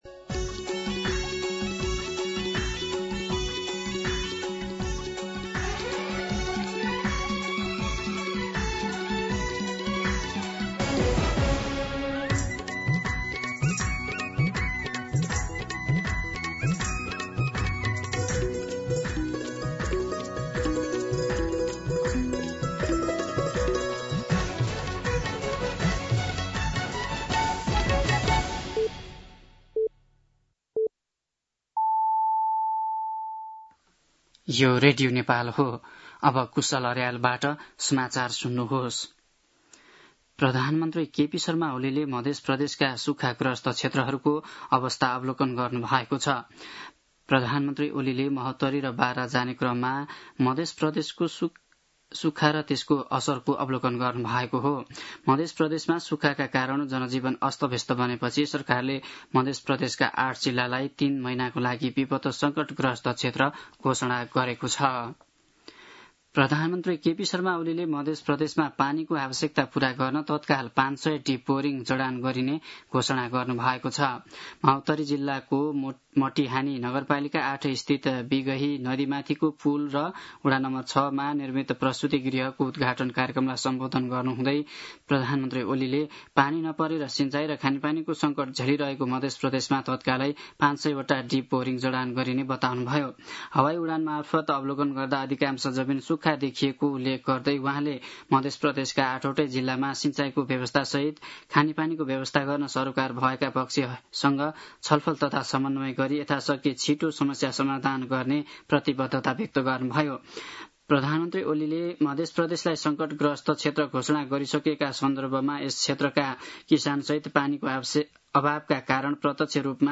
दिउँसो ४ बजेको नेपाली समाचार : ९ साउन , २०८२